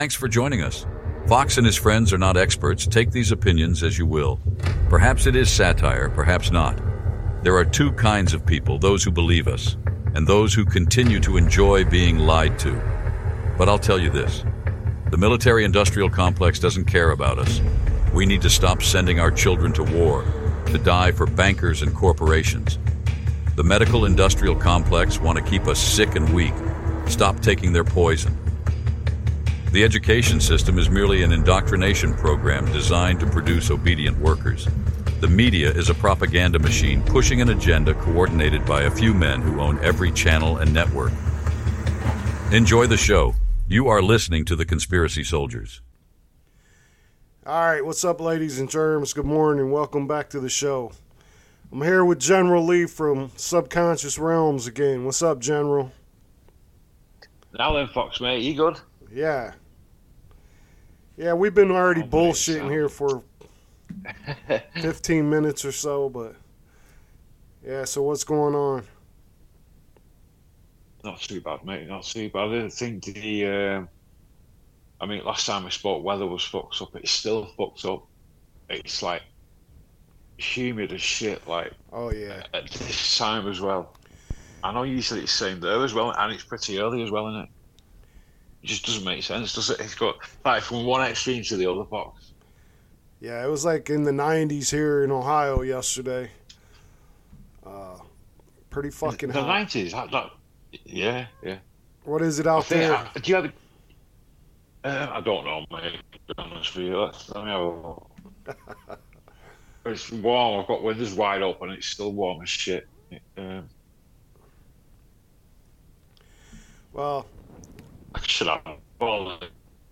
Occasional guest interviews.